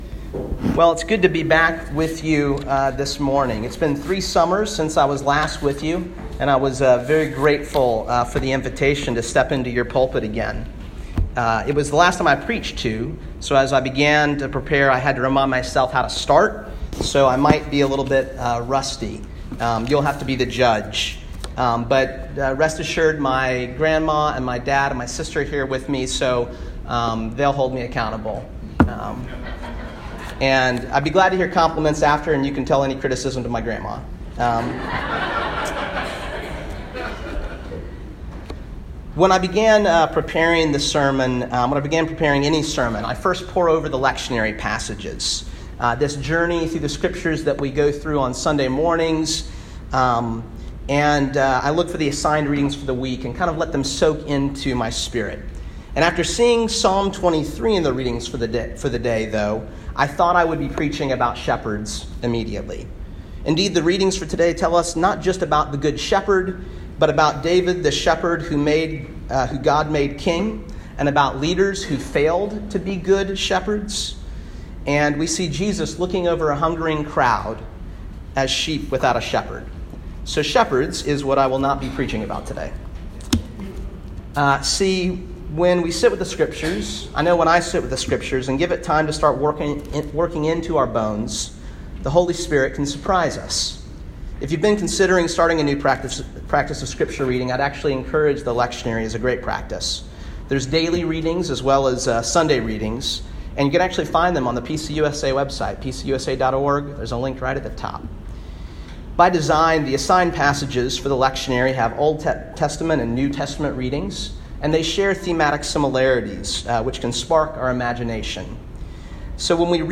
Preached July 22, 2018 at Highlands United Presbyterian Church, Jacksonville, FL